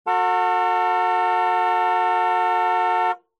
CLAXON DE UN COCHE BOCINA DE UN CARRO
EFECTO DE SONIDO DE AMBIENTE de CLAXON DE UN COCHE BOCINA DE UN CARRO
Claxon_de_un_coche_-_Bocina_de_un_carro.mp3